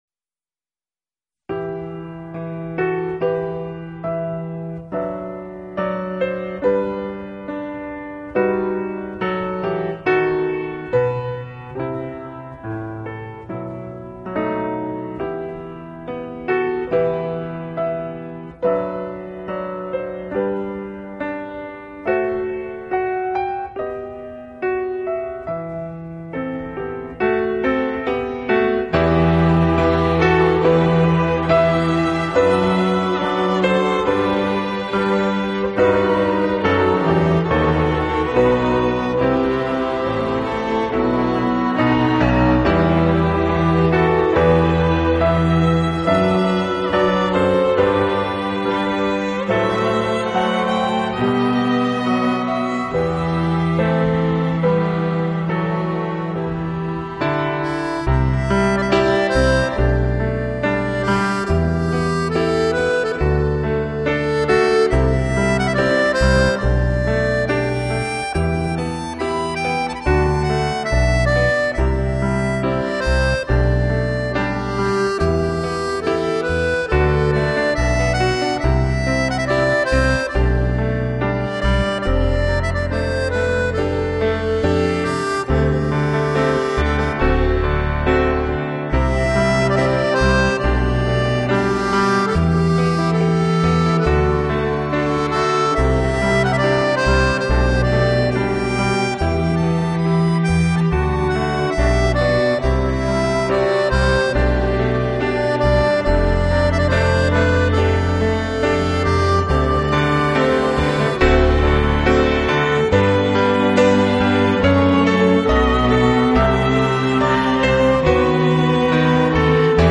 音乐类型：New Age / Piano Solo